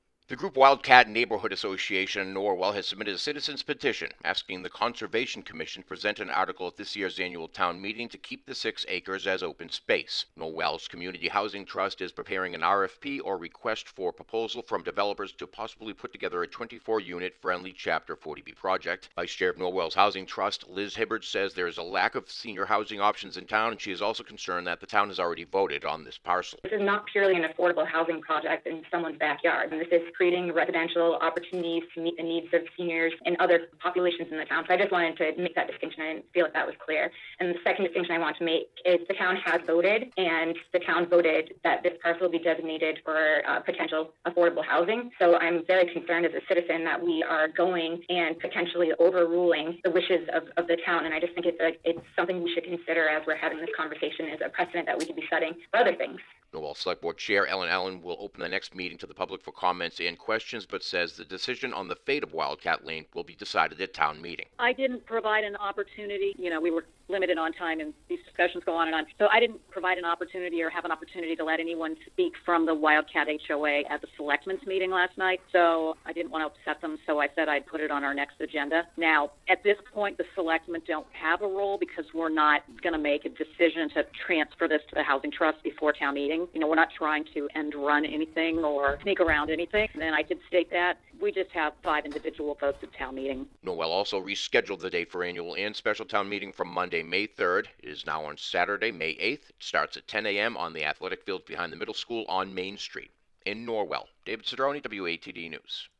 by | Mar 26, 2021 | News